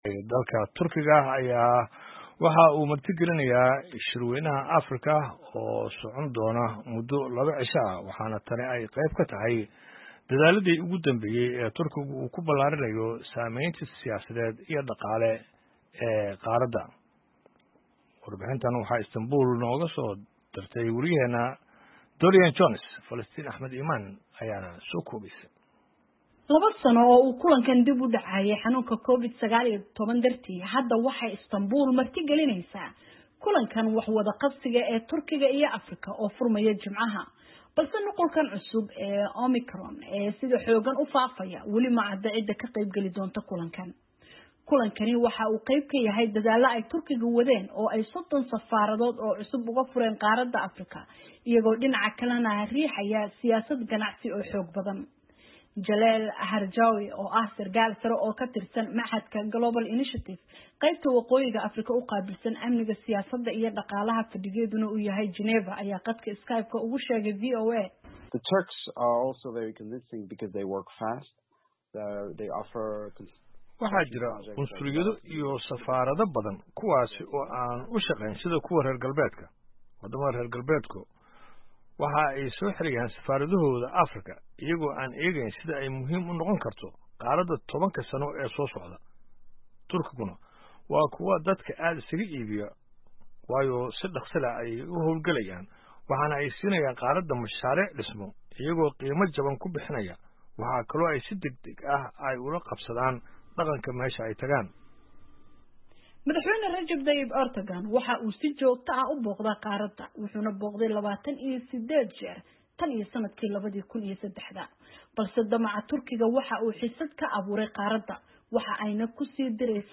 Warbixin ku saabsan shirka iskaashiga Turkiga iyo Africa